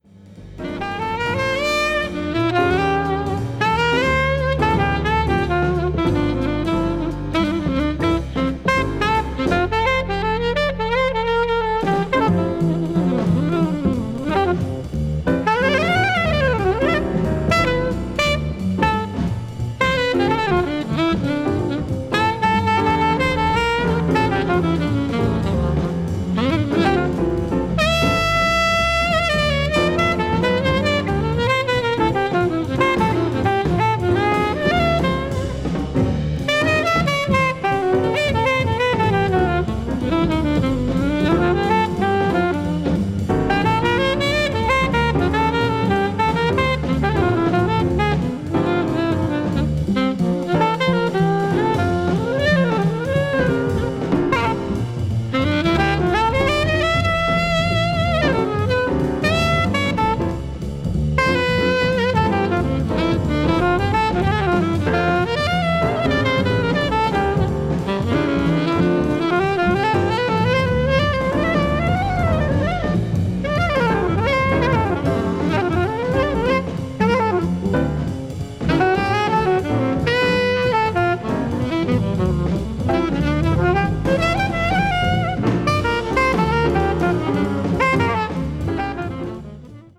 bebop   jazz standard   modern jazz   mood jazz